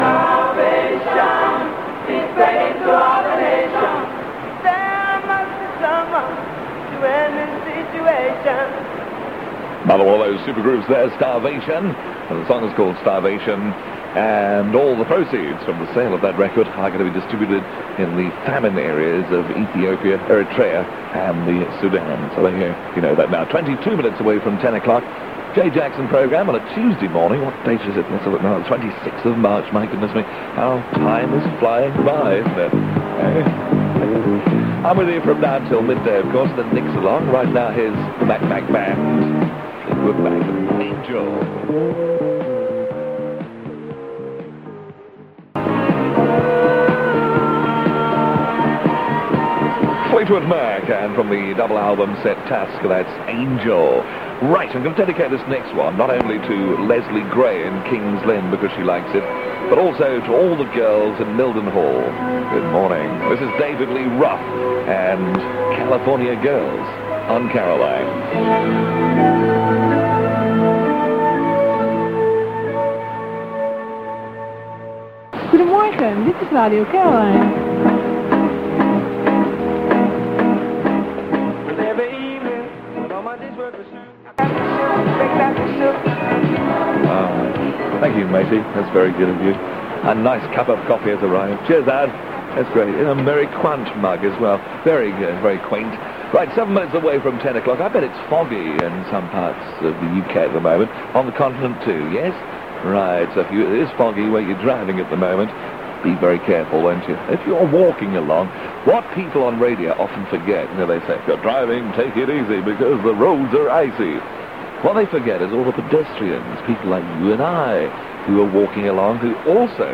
on Caroline International, broadcasting on 585 kHz, from the morning of 26th March 1985